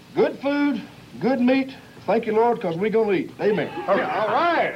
prayer.mp3